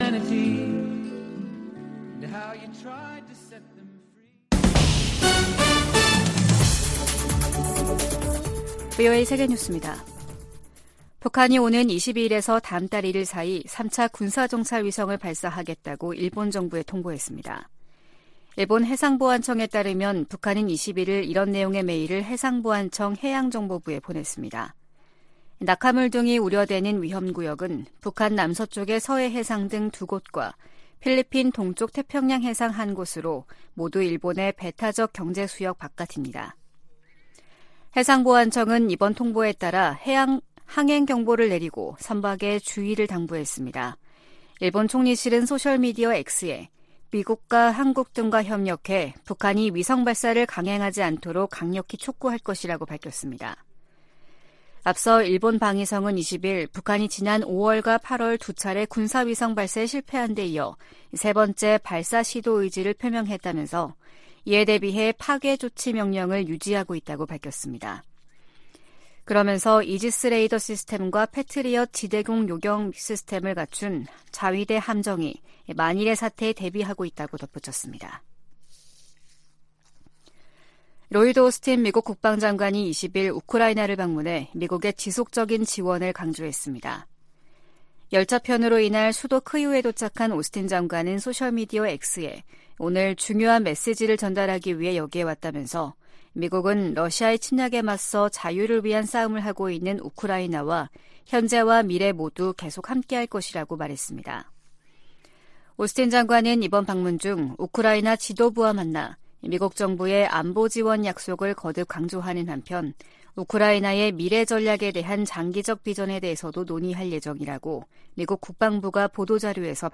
VOA 한국어 아침 뉴스 프로그램 '워싱턴 뉴스 광장' 2023년 11월 21일 방송입니다. 아시아태평양경제협력체(APEC) 21개 회원국들이 다자무역의 중요성을 강조하는 ‘2023 골든게이트 선언’을 채택했습니다. 한국 합동참모본부는 북한에 3차 군사정찰위성 발사 준비를 중단하라는 경고성명을 냈습니다. 미중 정상회담으로 두 나라간 긴장이 다소 완화된 것은 한반도 정세 안정에도 긍정적이라고 전문가들이 진단했습니다.